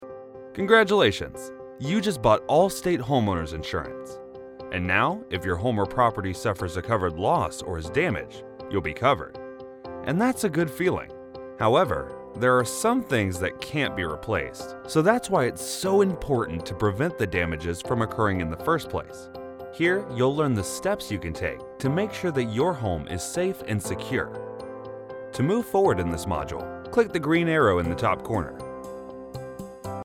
Englisch (Amerikanisch)
Unverwechselbar, Vielseitig, Warm, Freundlich, Corporate
E-learning